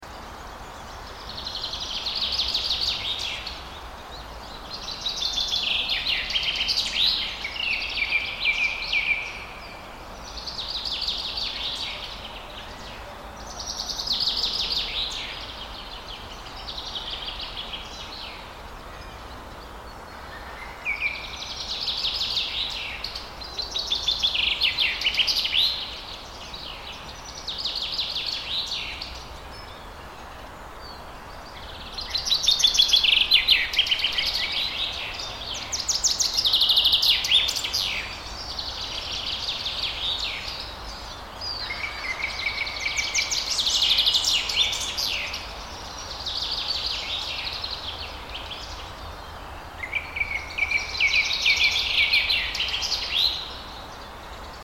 دانلود آهنگ جنگل 15 از افکت صوتی طبیعت و محیط
دانلود صدای جنگل 15 از ساعد نیوز با لینک مستقیم و کیفیت بالا
جلوه های صوتی